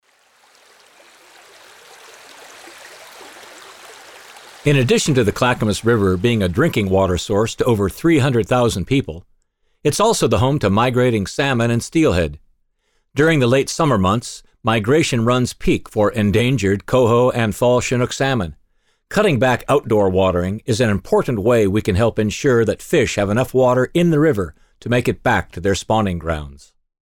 The audio PSAs (Public Service Announcements) below are designed to inform and educate our customers on ways to be more efficient with their outdoor water use throughout the summer and how to turn down and shut off outdoor watering in the late summer in time for the fall fish migration in the Clackamas River.